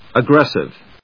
音節ag・gres・sive 発音記号・読み方
/əgrésɪv(米国英語), ʌˈgresɪv(英国英語)/
フリガナアグレシブ